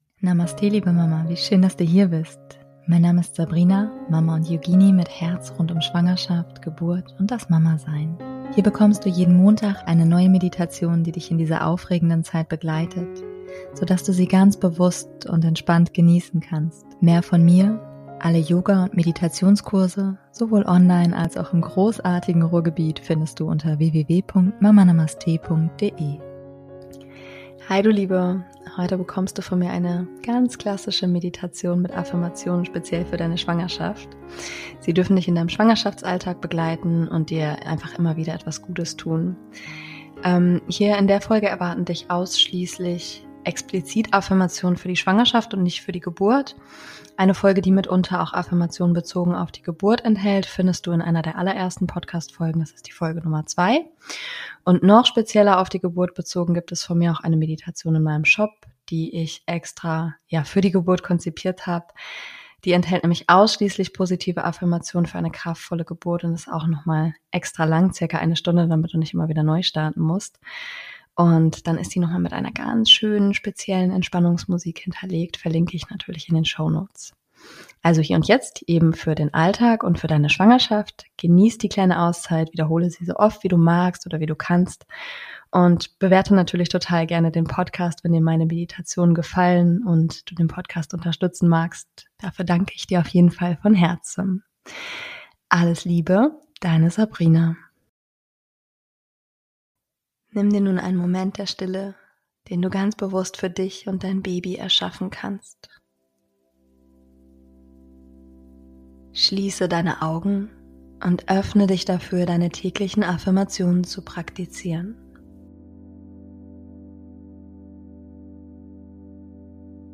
Heute bekommst du von mir eine ganz klassische Meditation mit Affirmationen speziell für deine Schwangerschaft. Sie dürfen dich in deinem Schwangerschafts Alltag begleiten und dir immer wieder etwas Gutes tun.